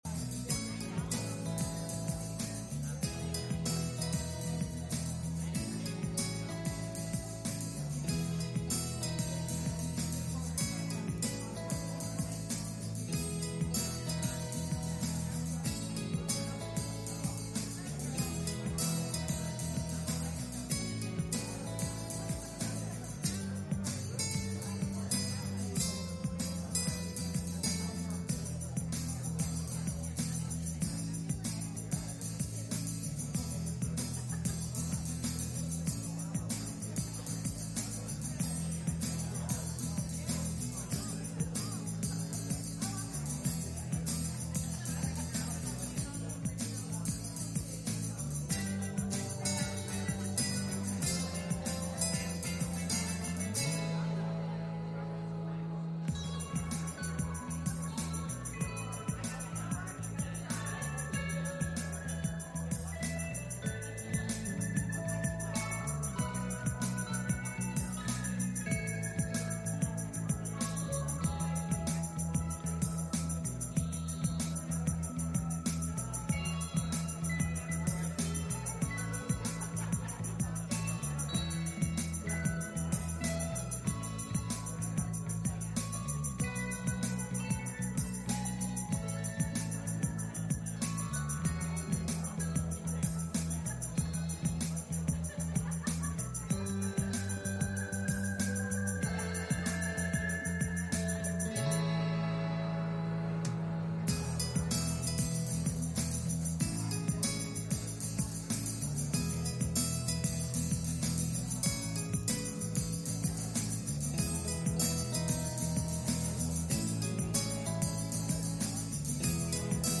1 Kings 19:1-8 Service Type: Sunday Morning « Can You Keep A Secret?